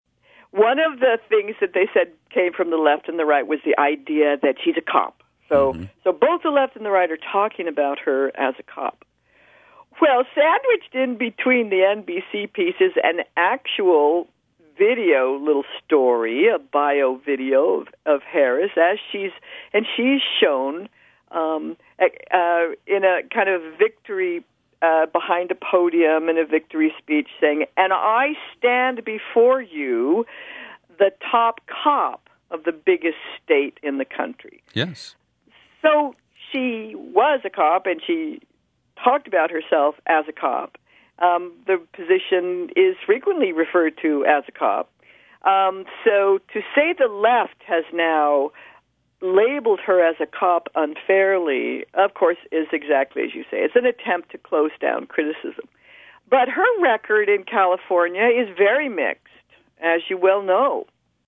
We also discuss the Democratic bubble placed over Biden and Harris, seeking to squelch honest criticism of their records in the interest of ending Trump’s presidency.  It’s a lively conversation that touches on the convention and many aspects of the 2020 campaign.